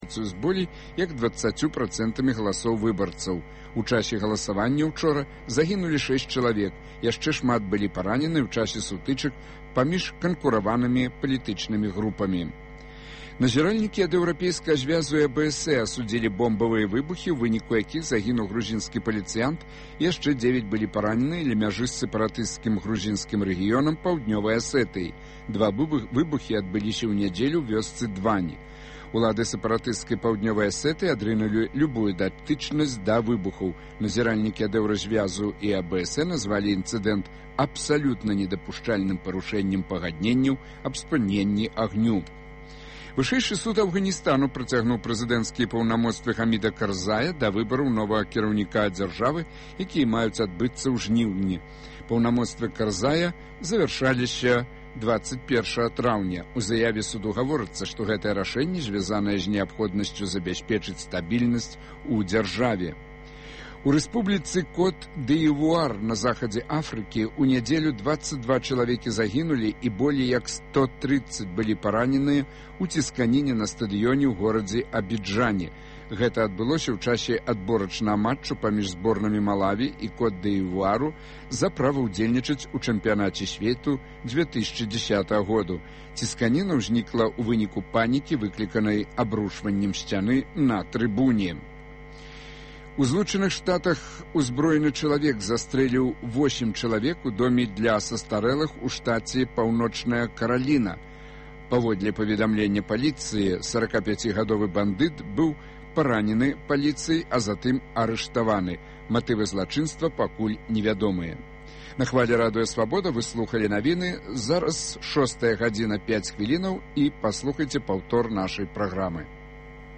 Круглы стол аналітыкаў, абмеркаваньне галоўных падзеяў тыдня